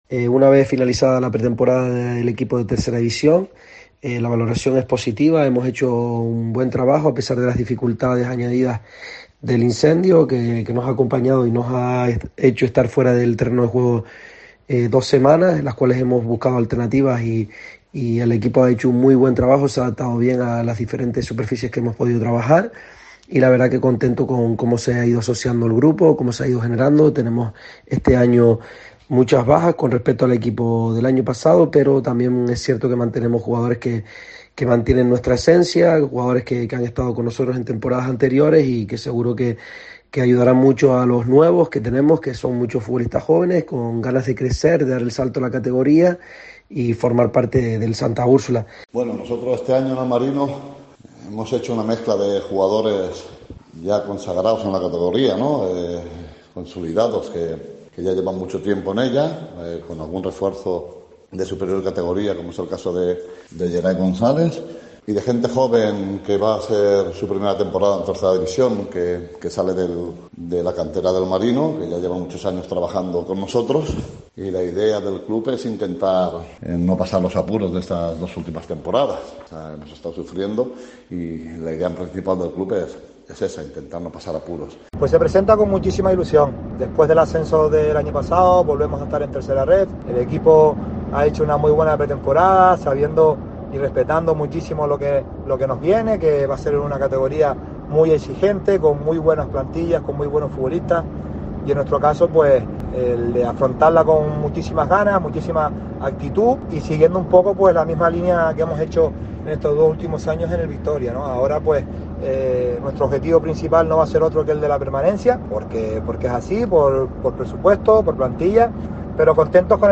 Los seis entrenadores de los representantes tinerferños en la categoría, hacen balance de la pretemporada y del comienzo de la competición